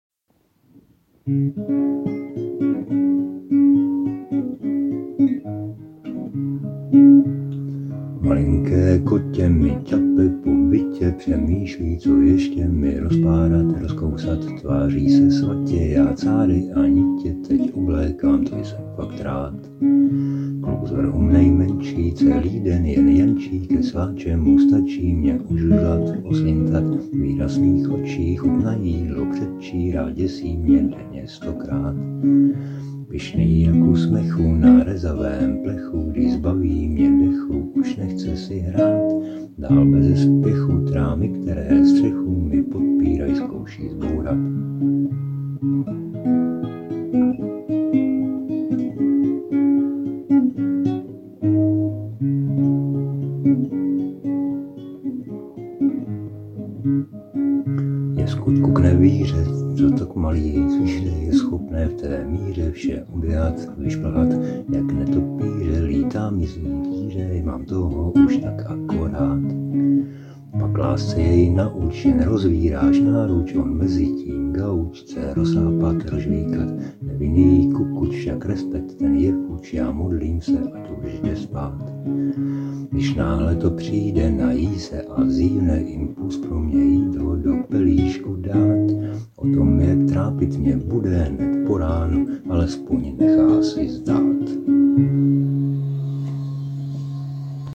Ještě jsem s ní neskončil, nejspíš změním melodii, abych jenom trapně nekopíroval melodii doprovodu a možná přidám i jeden (neopakující se) refrén.